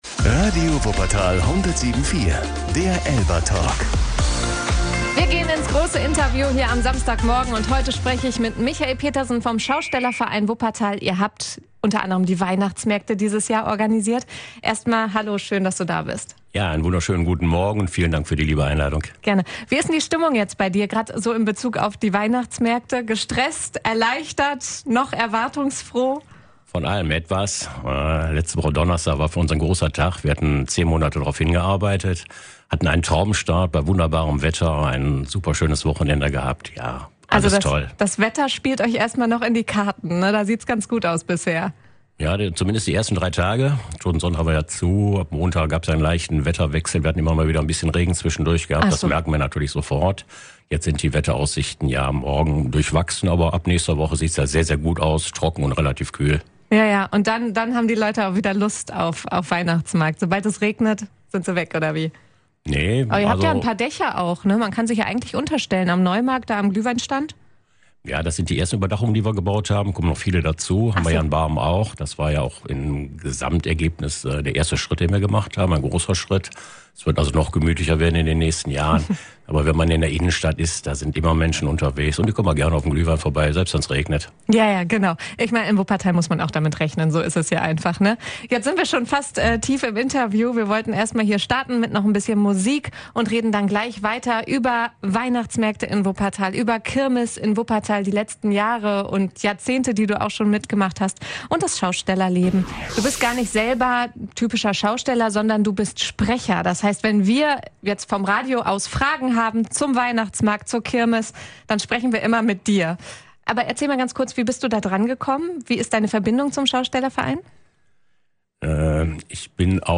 Hört euch hier das ganze Interview an: ein Blick hinter die Kulissen, wie Weihnachtsmärkte und Kirmes in Wuppertal entstehen und was dahinter steckt.